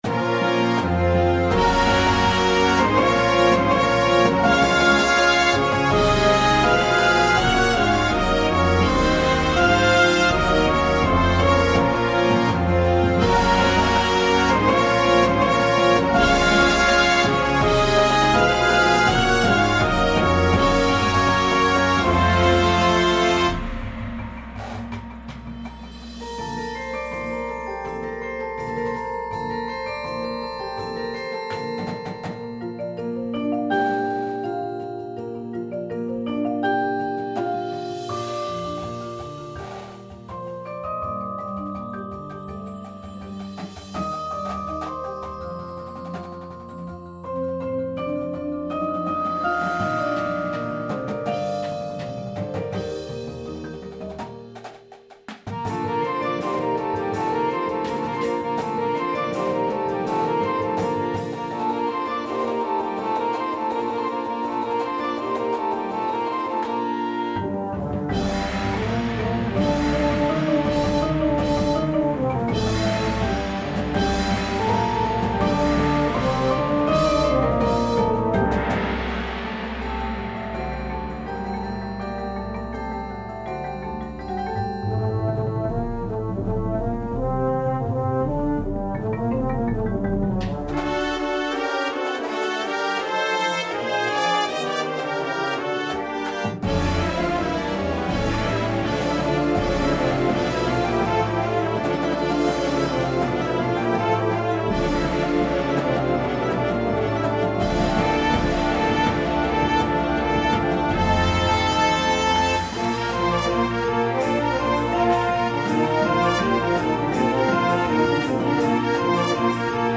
• Clarinet Duet, part 1 only
• Flute
• Alto Sax 1, 2
• Trumpet 1
• Horn in F
• Trombone 1, 2
• Tuba
• Snare Drum
• Bass Drums
• Front Ensemble